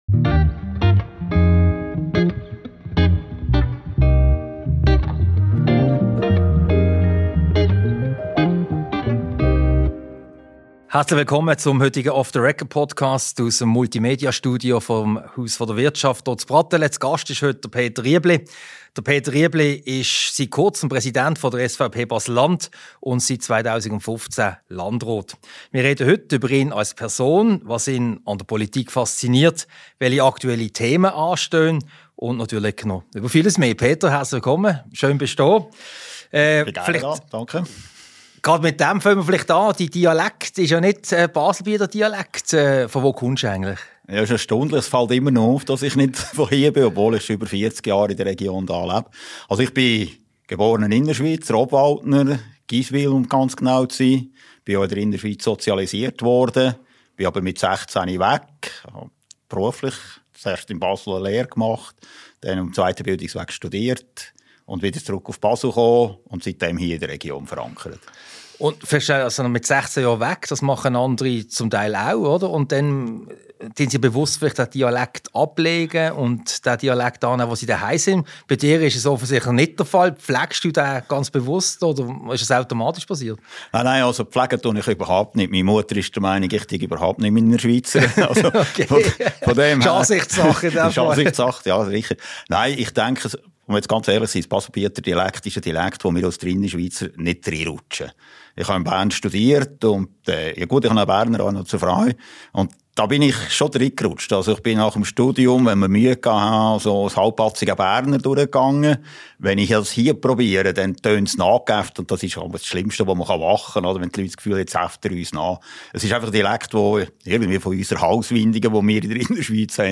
Mit Peter Riebli (Präsident SVP Baselland, Landrat). Ein Gespräch über seinen Werdegang, sein politisches Wirken als Landrat und bei der SVP Baselland, die DNA der SVP sowie natürlich über diverse aktuelle politische Themen.